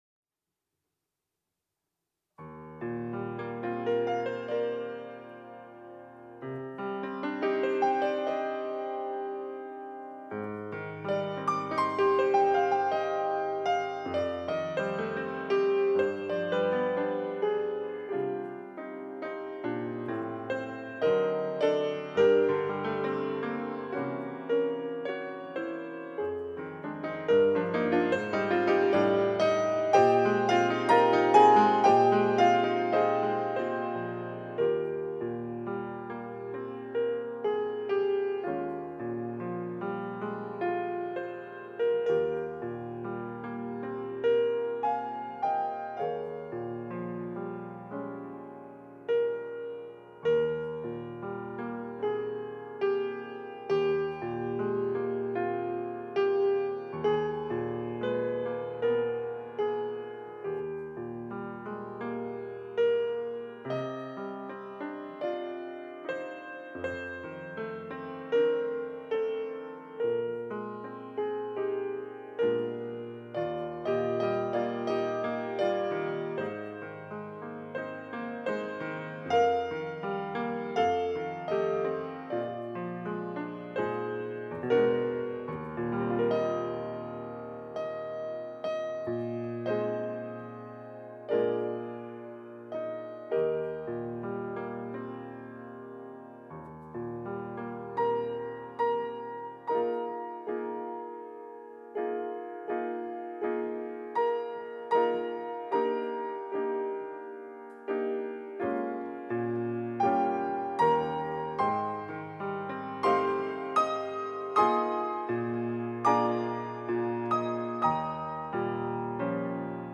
특송과 특주 - 내 평생에 가는 길